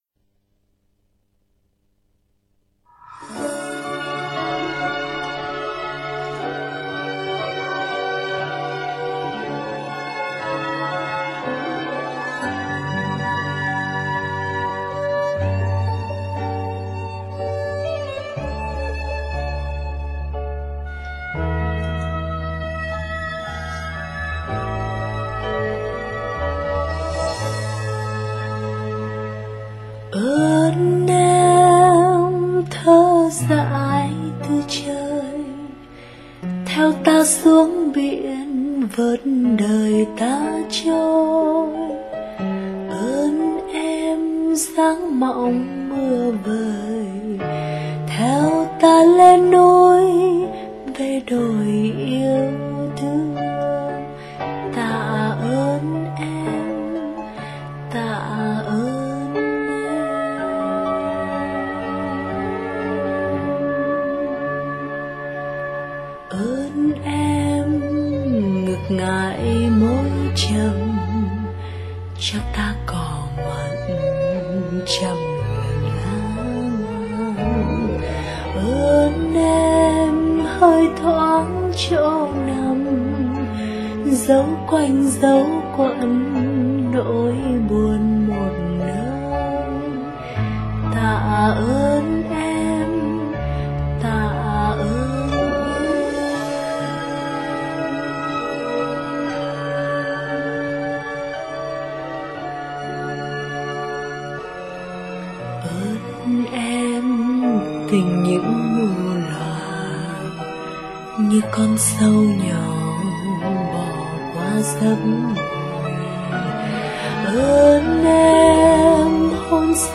giọng hát ngọt ngào truyền cảm